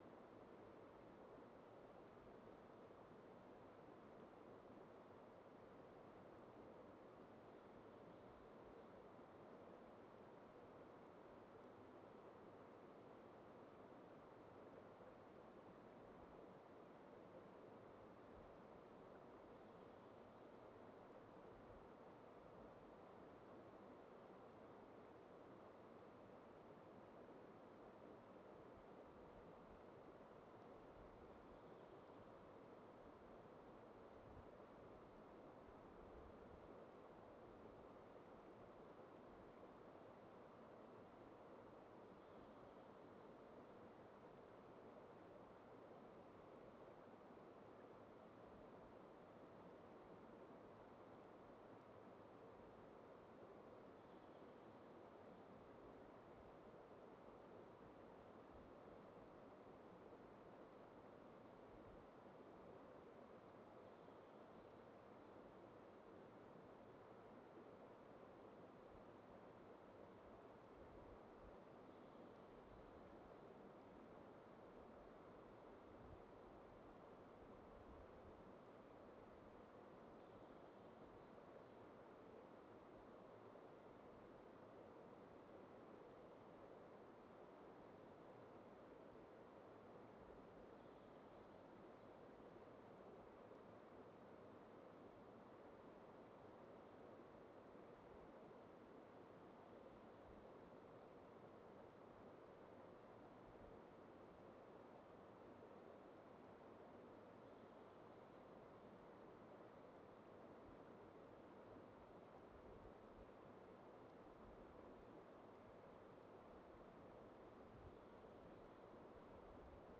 Quellrauschen in Bänder geteilt Band 63.wav